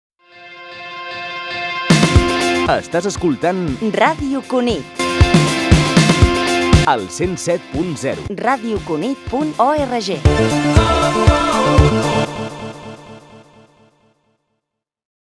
78e8a888fdc5cfd6b9a2a8a4d9a1f78eee9425bd.mp3 Títol Ràdio Cunit Emissora Ràdio Cunit Titularitat Pública municipal Descripció Indicatiu de l'emissora.